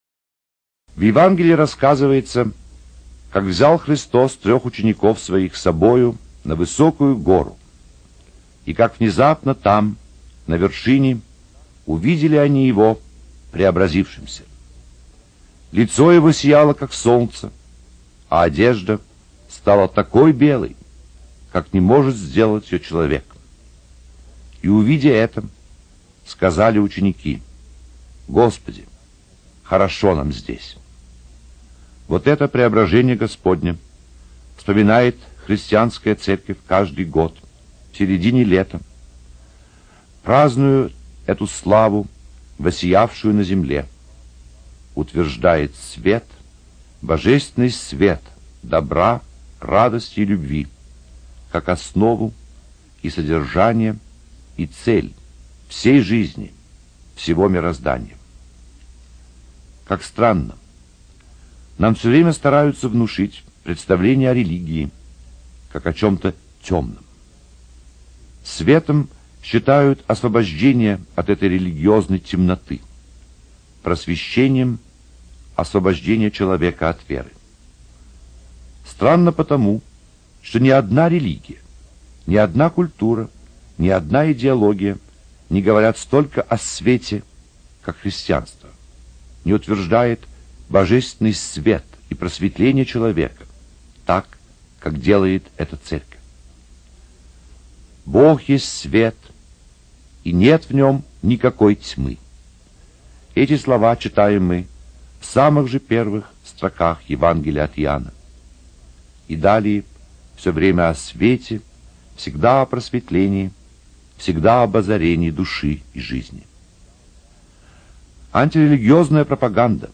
Беседа протопресвитера Александра Шмемана на Преображение
85_Prot_A_SHmeman_Preobrazhenie-Gospodne_Prazdnik-sveta.mp3